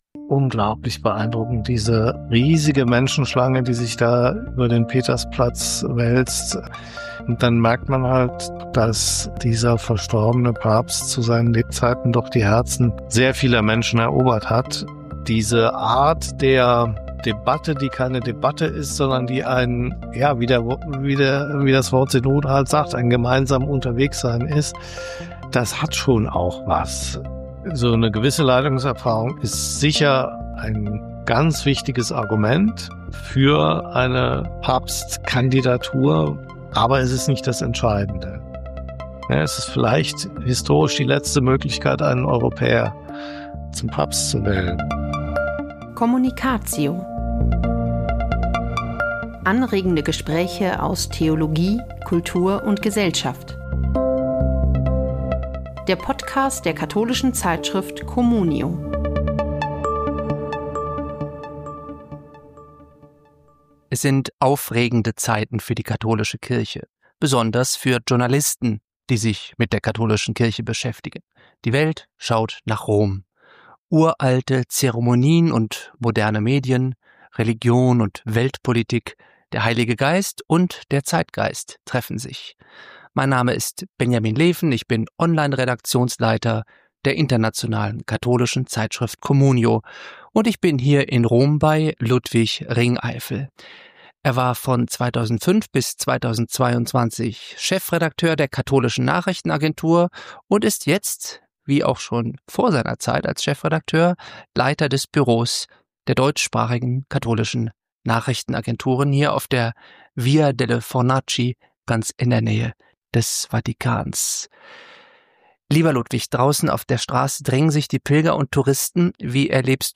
mit dem Journalist und Vatikan-Kenner